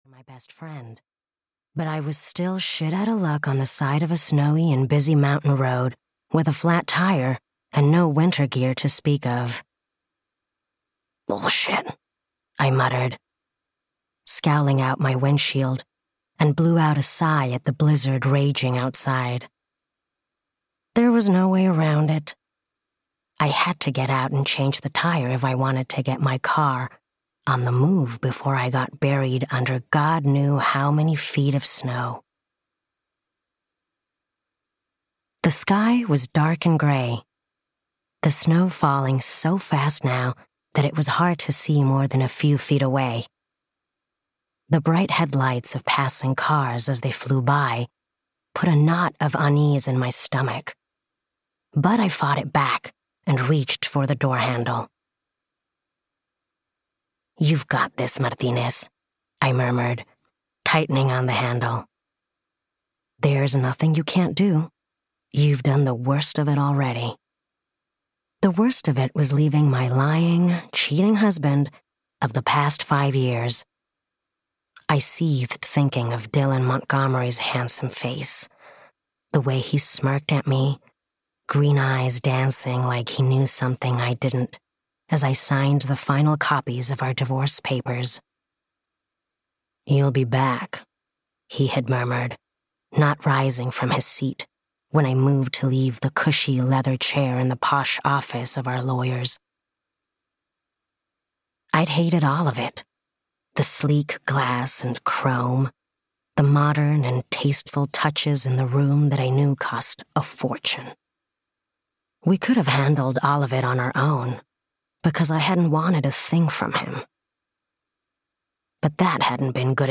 New Girl In Town (EN) audiokniha
Ukázka z knihy